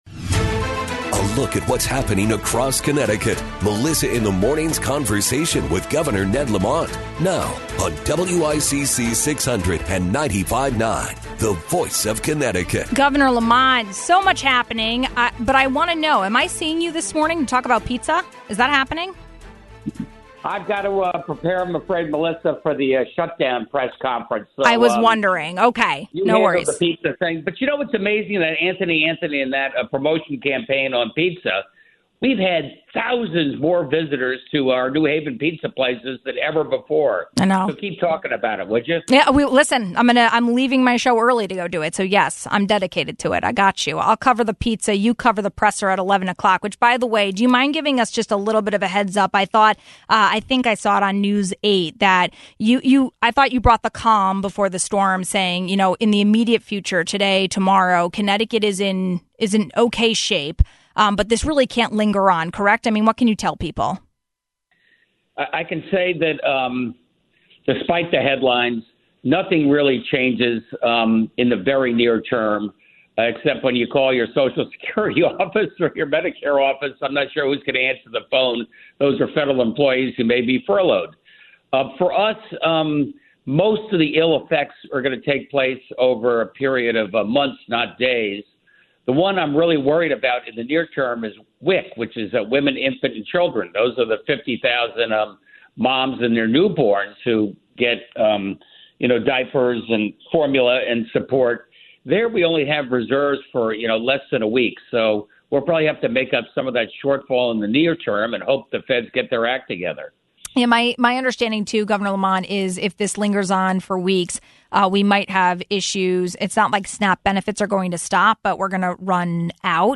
So, what is the plan to fill her position and the rest of the PURA commissioner roles? We asked Governor Lamont about that.